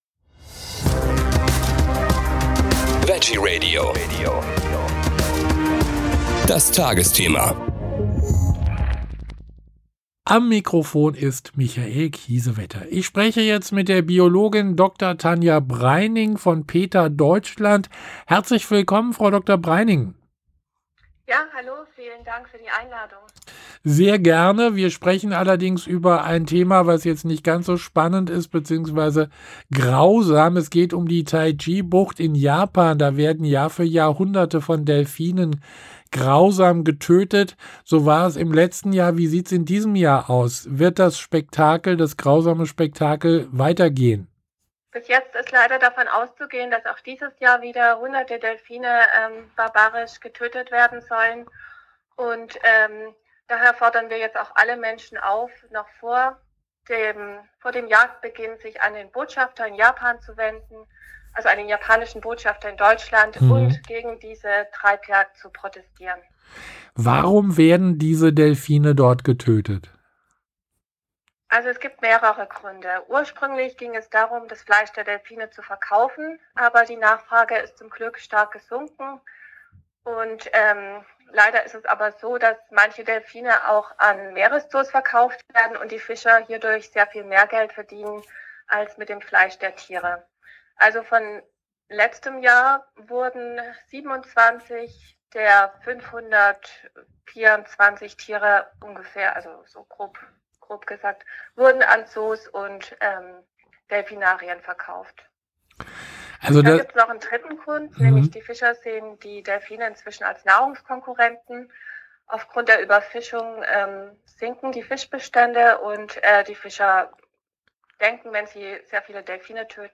Interview mit PETA